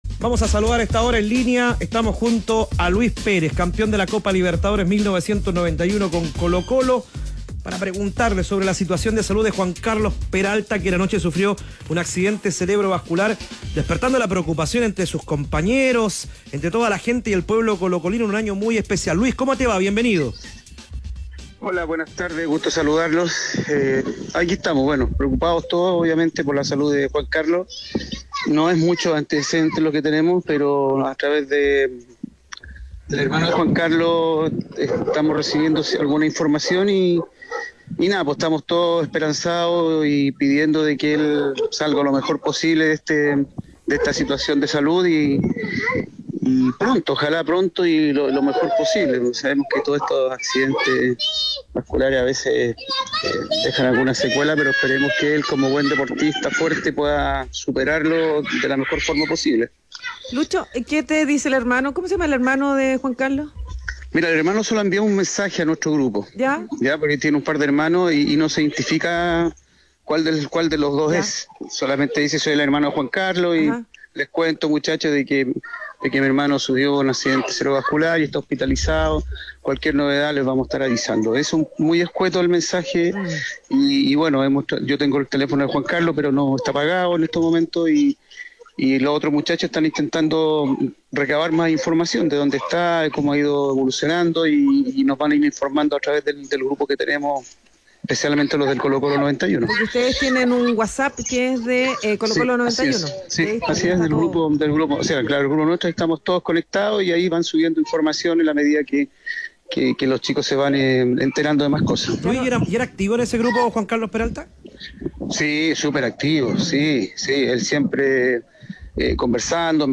En diálogo con Los Tenores de la Tarde, el histórico delantero del “Cacique” entregó detalles sobre el complejo momento de salud que atraviesa su excompañero tras sufrir un ACV.